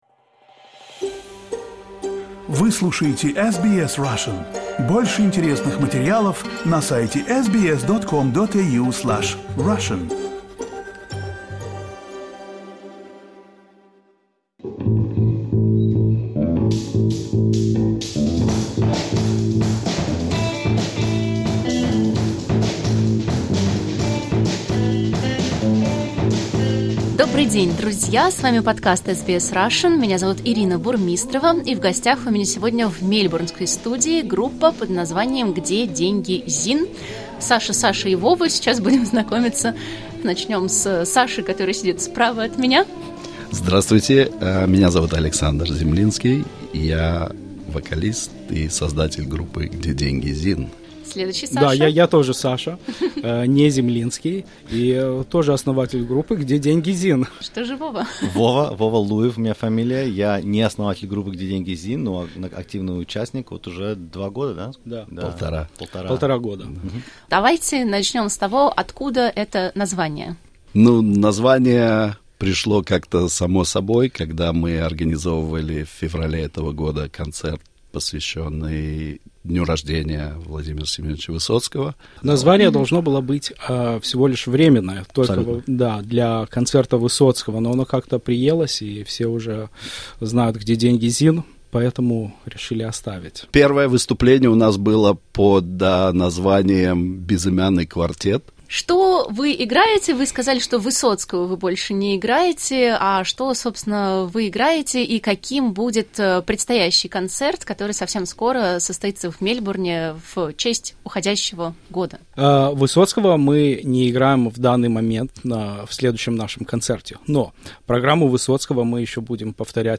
Участники группы "Где деньги, Зин?" в студии SBS в Мельбурне.